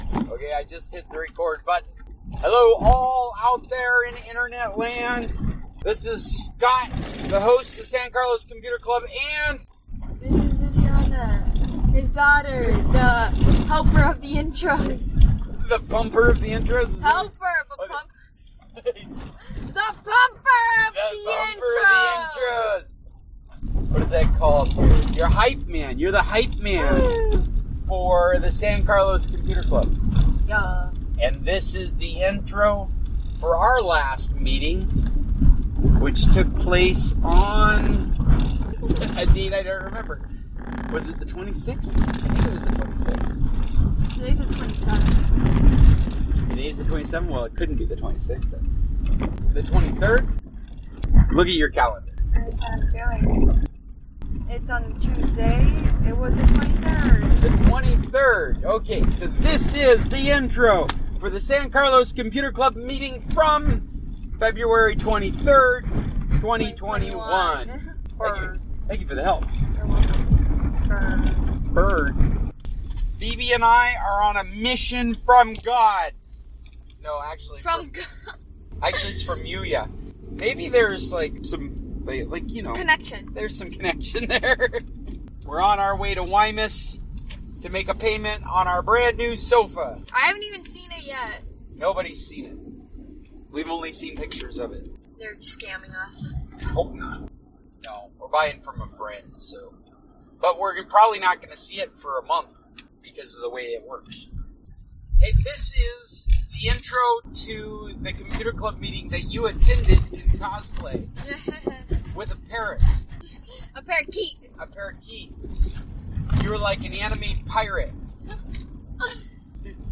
Hello all you tech-heads out there. This is our latest meeting.
Another meeting with great recommendations and informed commentary from our members.